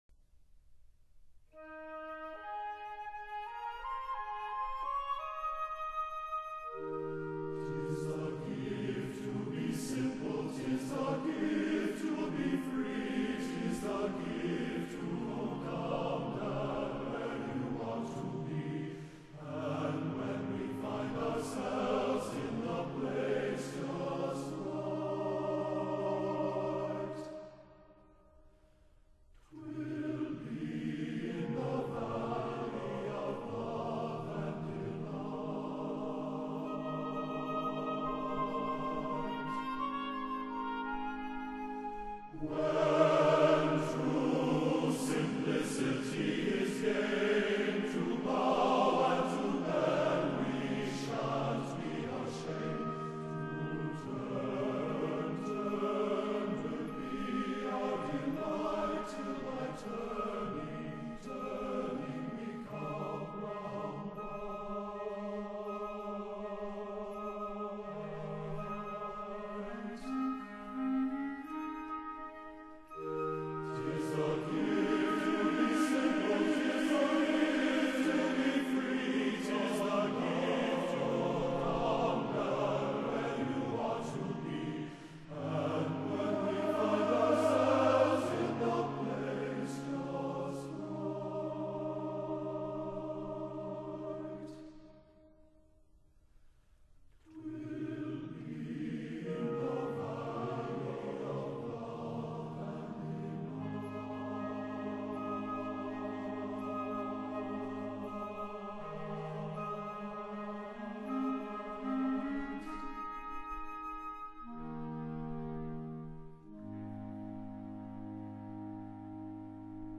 這張CD也是在那裏錄下的。
罕見的龐大人聲能量、出奇和諧的合唱以及經常出現的排山倒海音壓對於音響系統都是 嚴格的考驗。